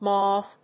発音記号
• / móʊv(米国英語)
• / mˈəʊv(英国英語)